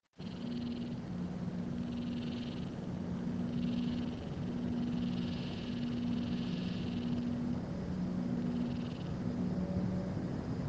All-in-One - Luqid Kühler, komisches Geräusch
Hallo, Ich habe mir vor einigen Monaten den MSI MAG CoreLiquid 240R Kühler gekauft. Seit einiger Zeit nehme ich ein "Gurgel" Geräusch wahr.
Anhänge Gurgeln.ogg Gurgeln.ogg 21,7 KB